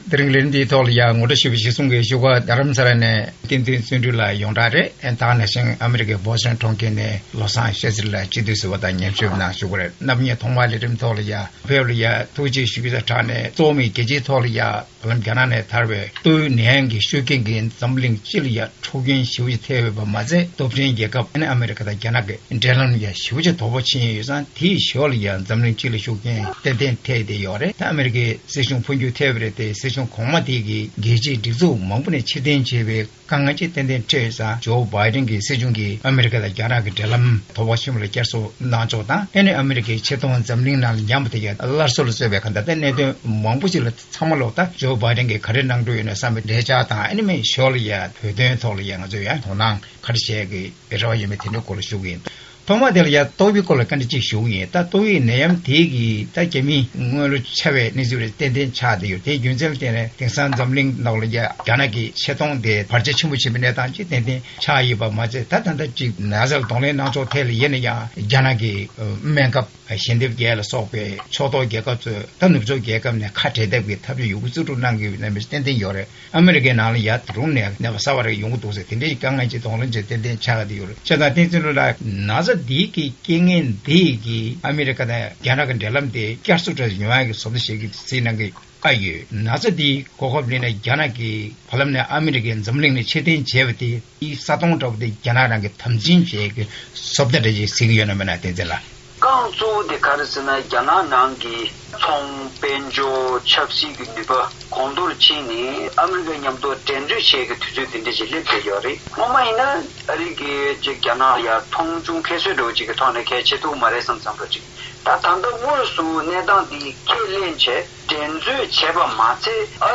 གླེང་མོལ་ཞུས་པ་ཞིག